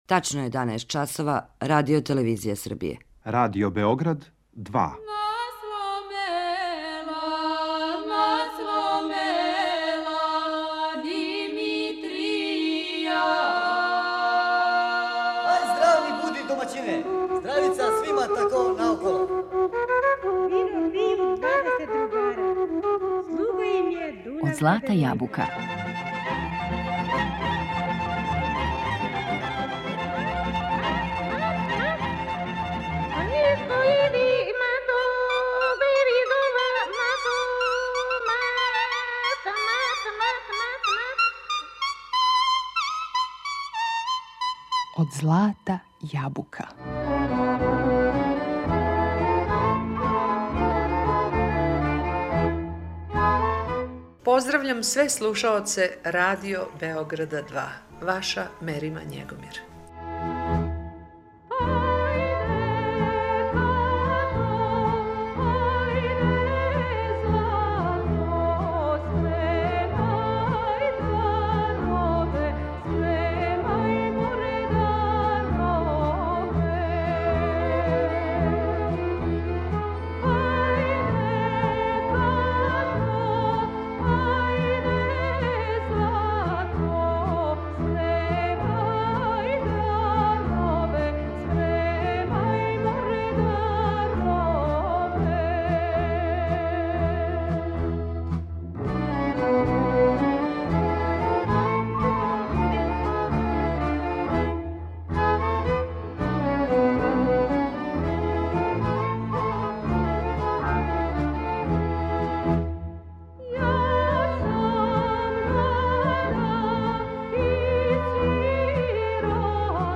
У емисији ћете чути документарни материјал који је забележен у априлу 2016. године.